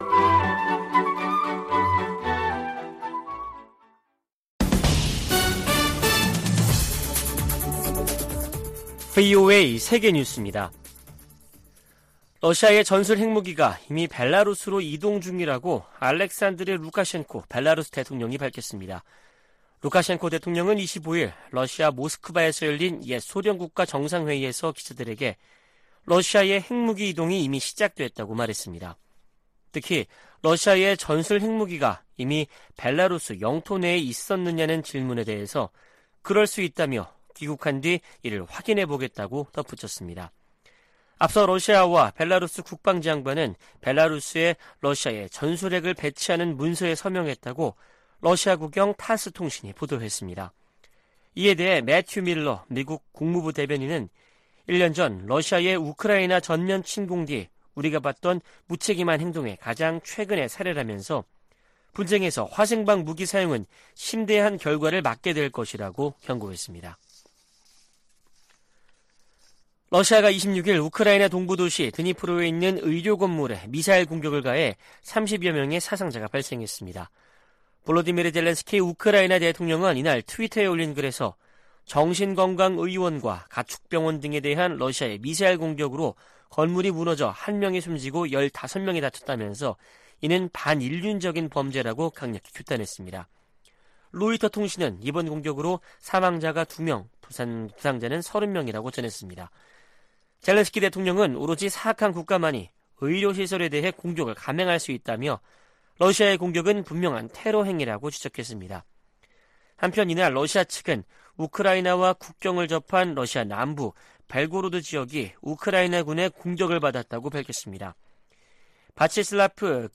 VOA 한국어 아침 뉴스 프로그램 '워싱턴 뉴스 광장' 2023년 5월 27일 방송입니다. 한국이 자력으로 실용급 위성을 궤도에 안착시키는데 성공함으로써 북한은 우주 기술에서 뒤떨어진다는 평가가 나오고 있습니다. 워싱턴 선언은 한국에 대한 확정억제 공약을 가장 강력한 용어로 명시한 것이라고 미 고위 당국자가 평가했습니다. 미국은 중국의 타이완 공격을 억제하기 위해 동맹국들과 공동 계획을 수립해야 한다고 미 하원 중국특별위원회가 제언했습니다.